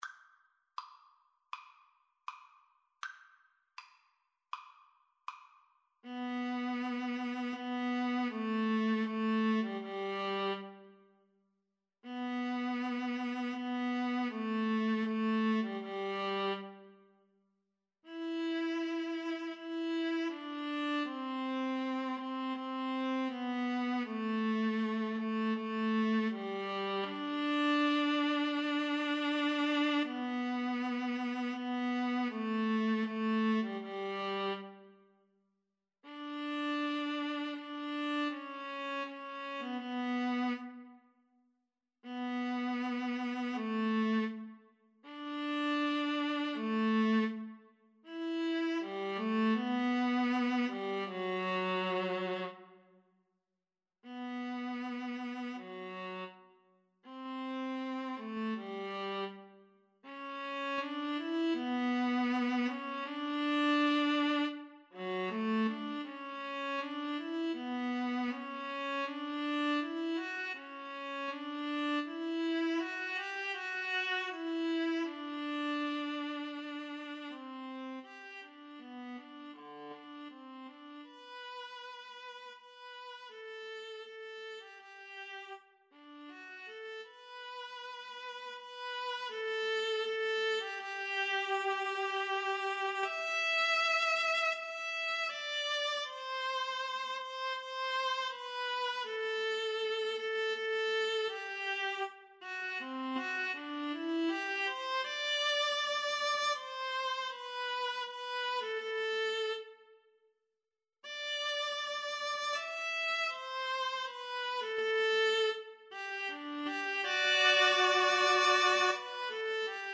Poco lento =80 Poco lento =80
4/4 (View more 4/4 Music)
Classical (View more Classical Viola-Cello Duet Music)